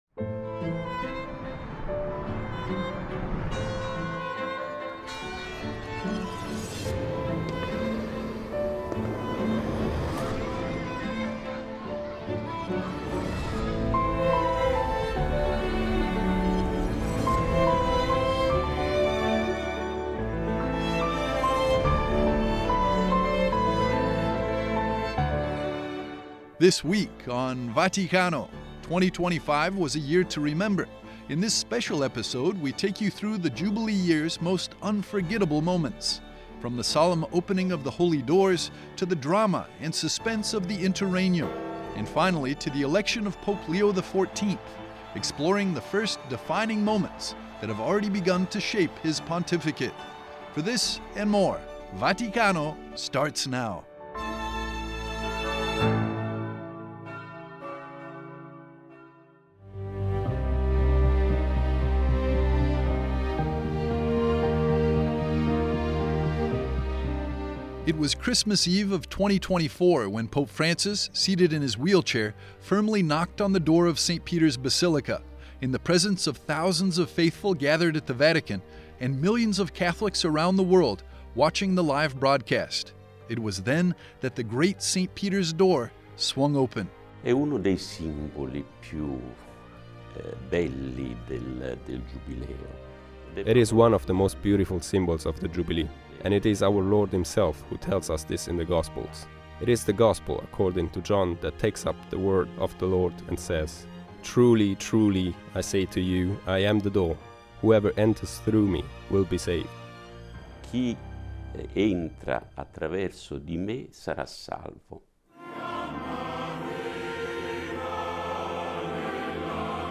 Presenting the latest news from the Vatican with excerpts and analysis of the Holy Father’s recent audiences and writings, newsmaker interviews, highlights of recent events, and feature segments- all from the heart of the Universal Church.